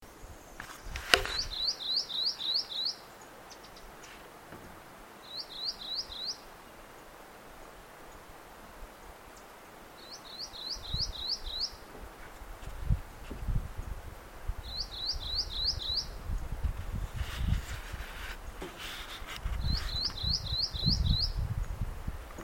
Chiví Coronado (Hylophilus poicilotis)
Nombre en inglés: Rufous-crowned Greenlet
Condición: Silvestre
Certeza: Fotografiada, Vocalización Grabada